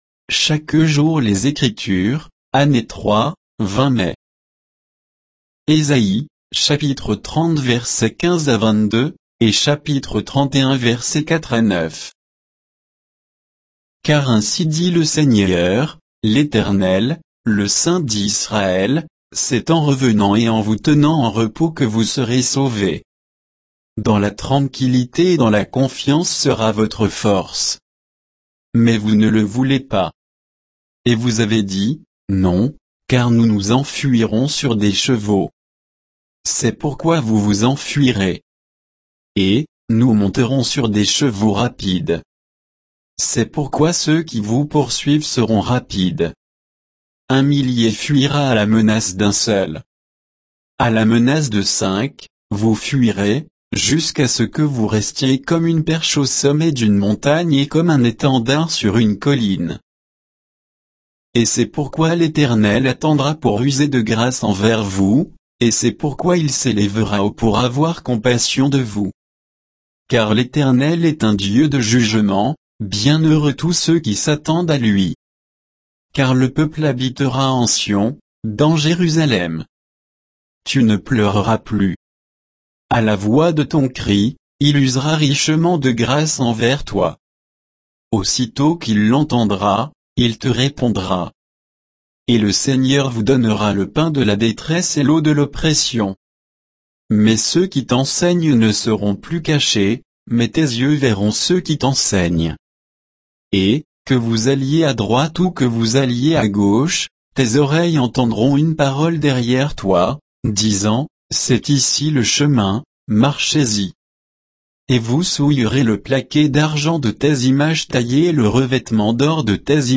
Méditation quoditienne de Chaque jour les Écritures sur Ésaïe 30